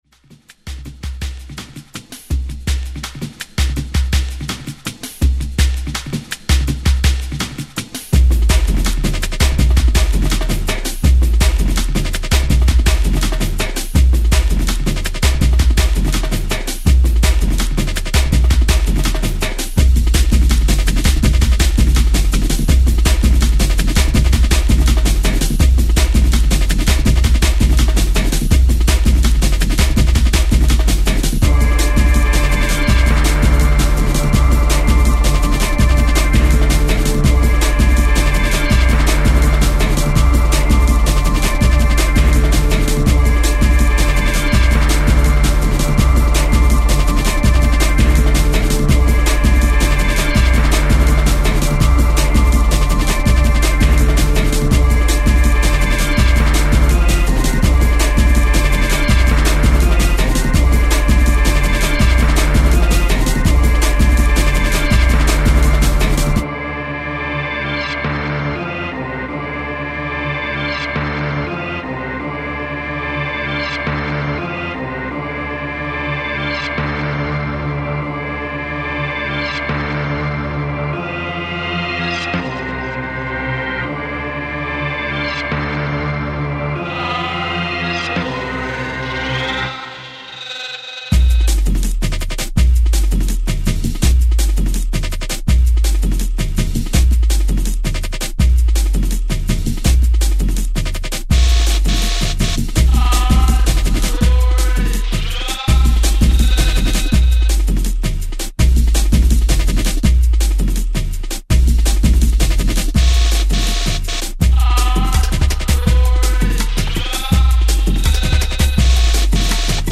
heavyweight Jungle style